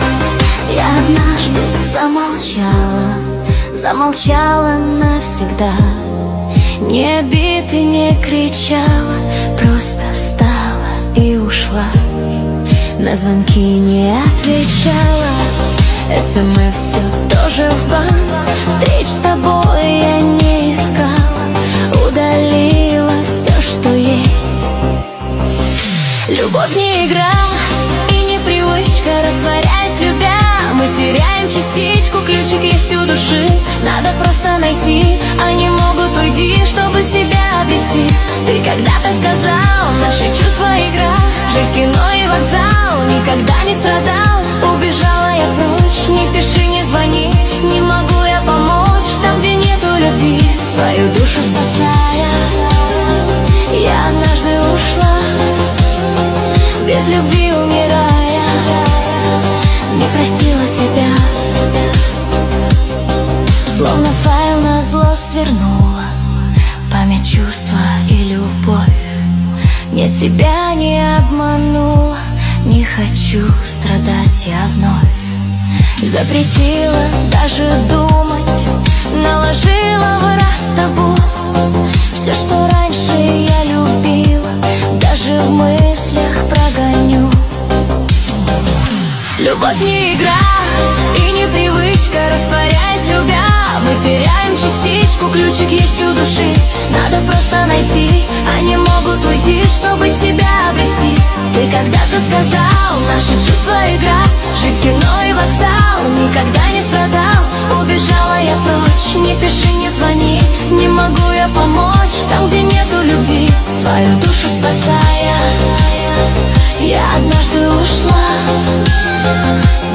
Качество: 320 kbps, stereo
Нейросеть Песни 2025